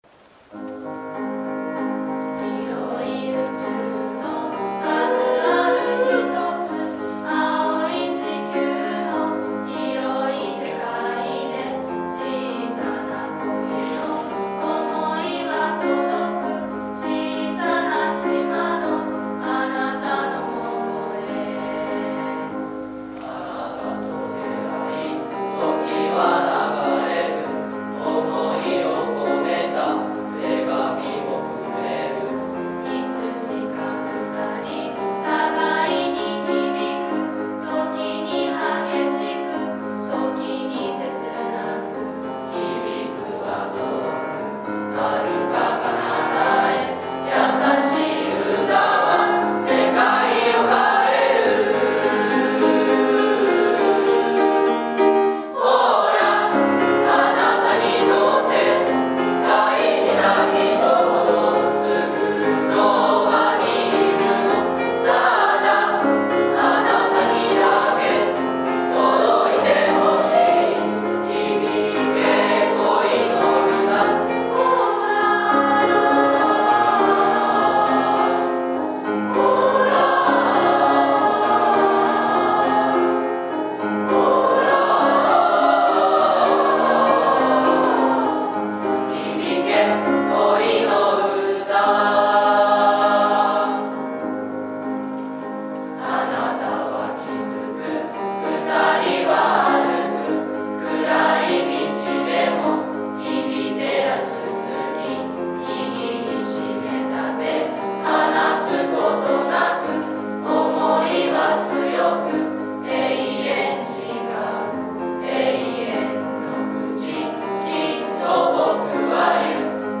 ２年生・合唱コンクール
今日は２年生合唱コンクールが行われました！！ 曲名は「小さな恋の歌」！！
全体での練習は牽制しているのか声が小さかったですが、、、 ほどよい緊張感で、どのクラスも頑張ってきた結果を十分に発揮する歌声を披露してくれました！！！